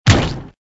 MG_cannon_hit_tower.ogg